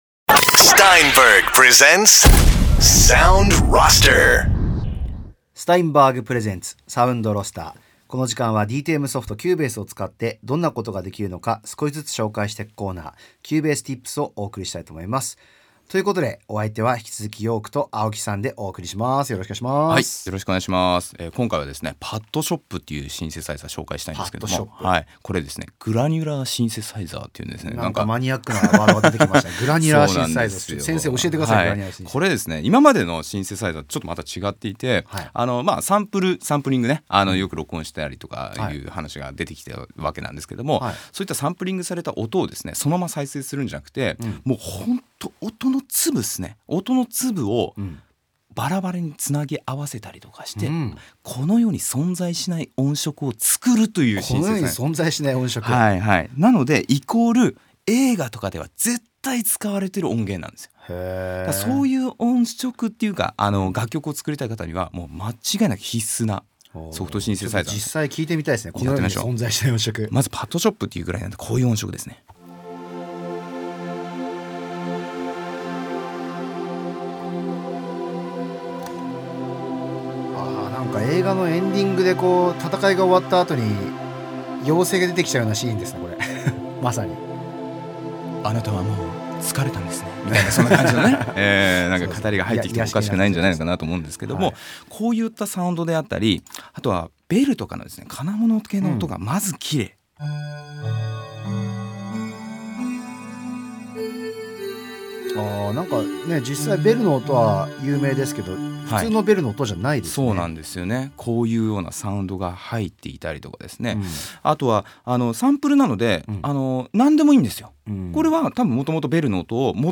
Steinberg が提供するラジオ番組「Sound Roster」。
映画などでは必ず使われる、その不思議な音に注目してください。